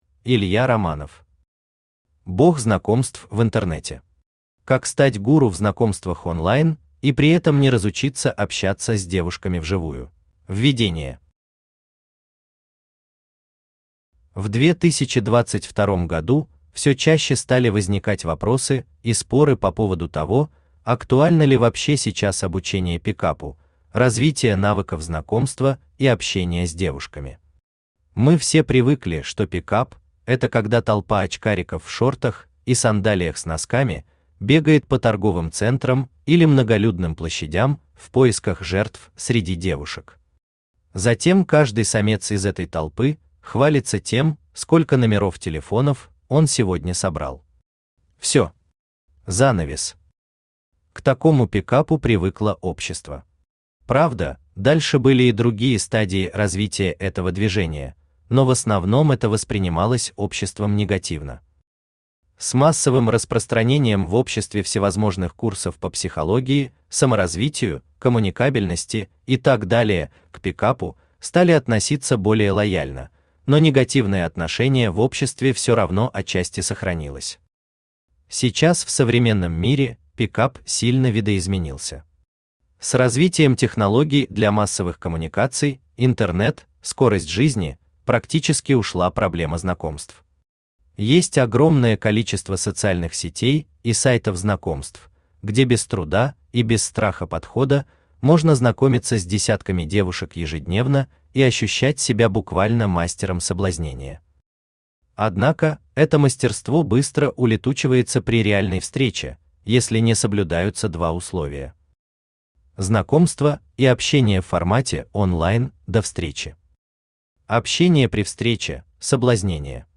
Аудиокнига Бог знакомств в интернете. Как стать ГУРУ в знакомствах онлайн и при этом не разучиться общаться с девушками вживую | Библиотека аудиокниг
Как стать ГУРУ в знакомствах онлайн и при этом не разучиться общаться с девушками вживую Автор Илья Романов Читает аудиокнигу Авточтец ЛитРес.